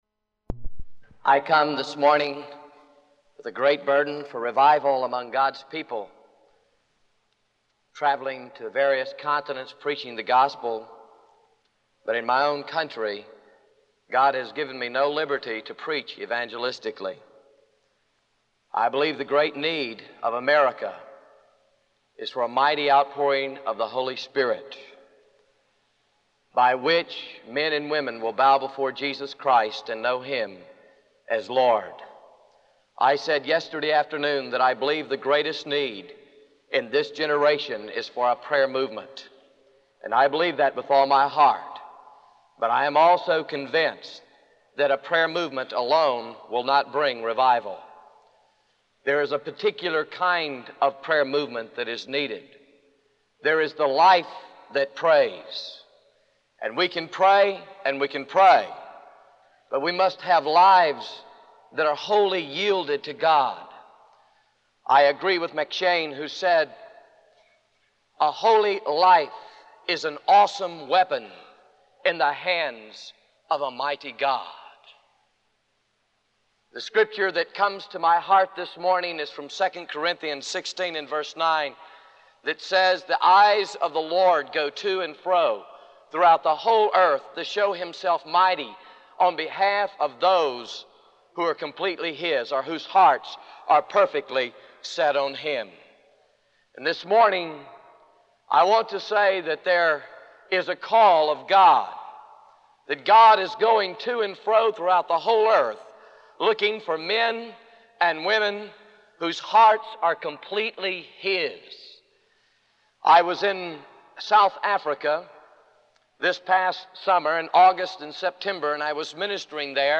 In this sermon, the speaker emphasizes the importance of knowing the holy God in order to dispel the power of evil in the land. He references Revelation 12:11, which states that believers overcome Satan through the blood of the Lamb, the word of their testimony, and their willingness to sacrifice their lives.